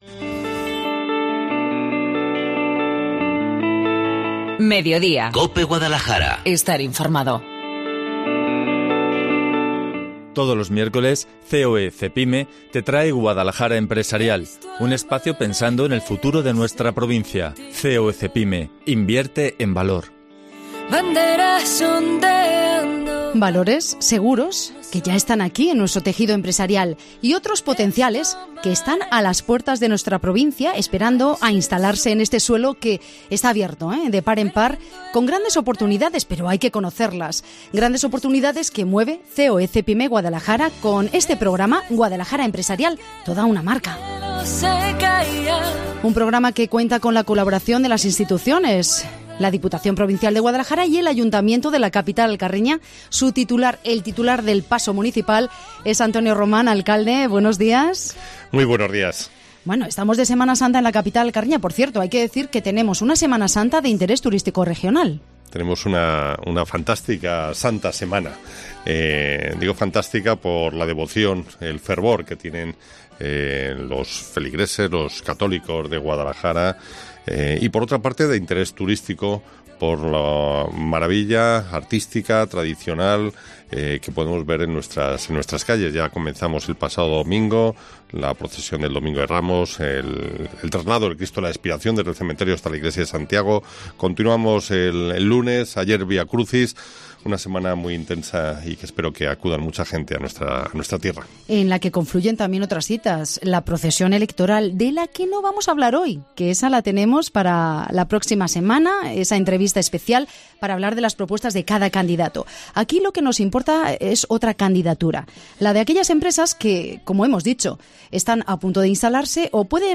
El alcalde de Guadalajara, Antonio Román, habla de "Guadalajara Empresarial" de CEOE CEPYME Guadalajara